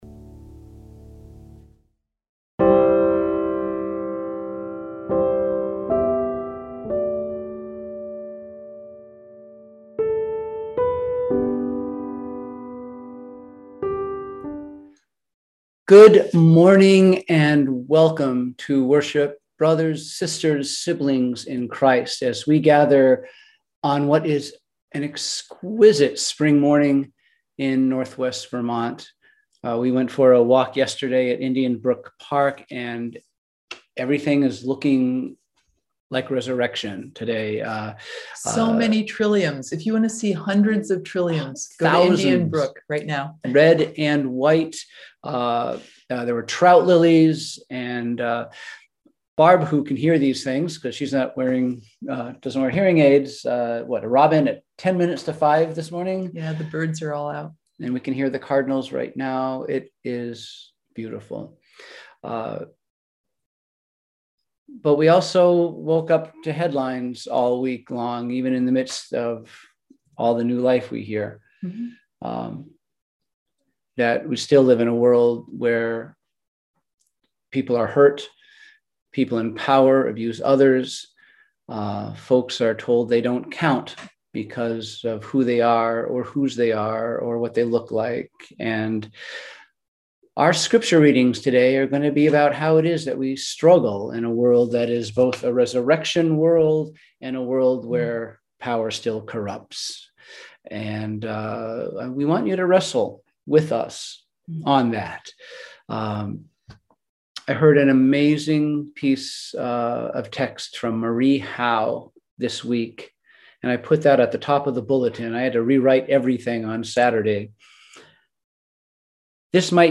We held virtual worship on Sunday, May 2, 2021 at 10:00AM!